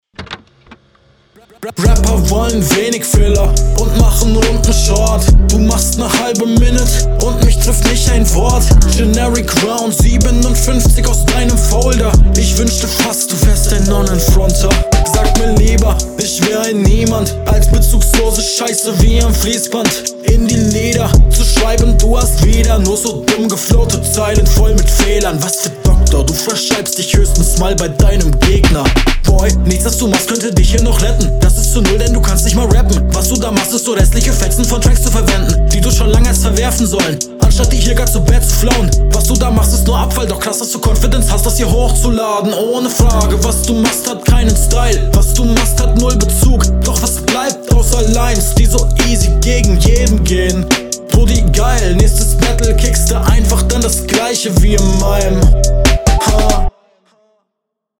Ich liebe die Reimsetzung, die spielt schön mit dem Beat, dadurch kommt der Flow auch …